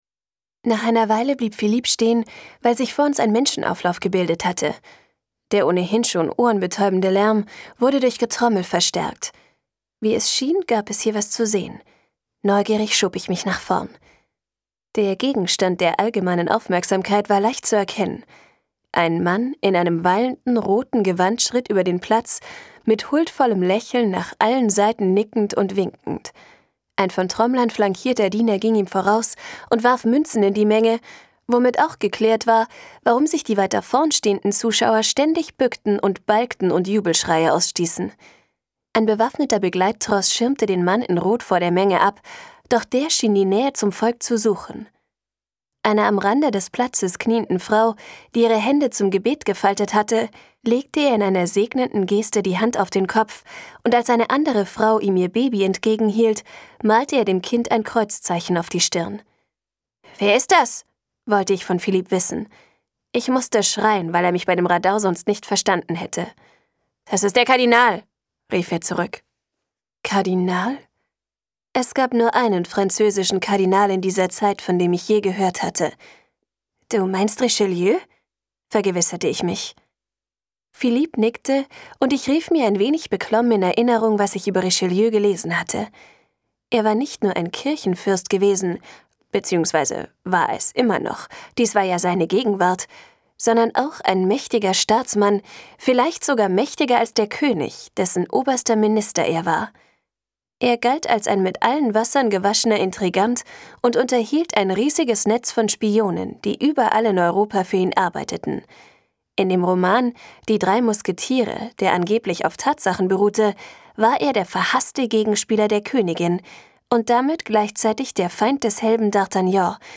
• Bücher ab 11 Jahre • Bücher ab 12 • Bücher ab 12 Jahre • Bücher für Kinder ab 12 • Bücher für Mädchen • Bücher für Teenager • Cassandra Clare • Cornelia Funke • Das verborgene Tor • Die goldene Brücke • Die magische Gondel • Erste Liebe • Fantasy; Kinder-/Jugendliteratur • Freundin • Freundschaft • für Jugendliche • Geheimbund • Geschenke für Jugendliche • Geschenke für Kinder • Geschichte • Gondel • Gregs Tagebuch • historisch • Hörbuch; Lesung für Kinder/Jugendliche • Jugendbuch • Jugendbuch ab 11 • Jugendbuch ab 12 • Jugendbücher • Jugendliche • Jugendroman • Jugenromane • Junge Belletristik • Liebe • London • Magie • Paris • Reisen • Romane für Jugendliche • Schule • sebastiano • Starke Mädchen • Teenager • Teenie • Teenies • Teens • Teeny • Tintenwelt • tribute von panem • Trilogie • Twilight • Venedig • Welten • Young Adult • Zauber • Zauberer • Zeitreise • Zeitreise; Kinder-/Jugendliteratur